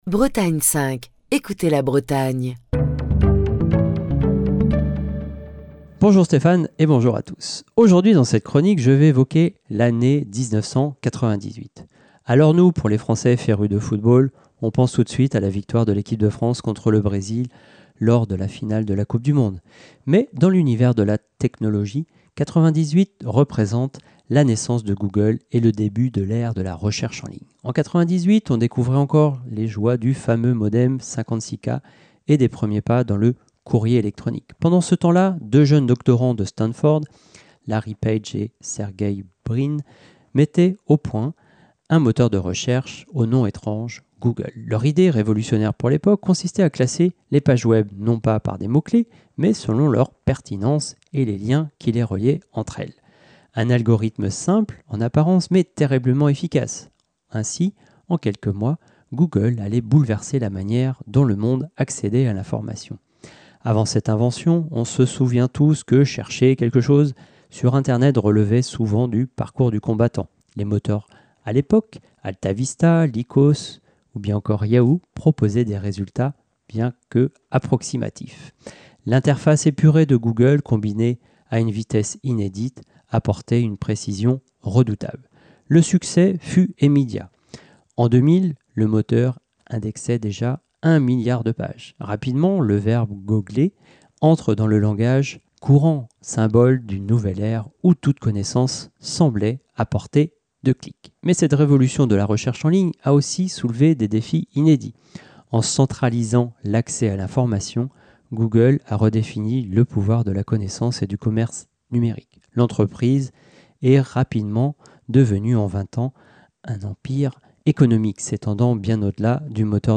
Chronique du 23 octobre 2025.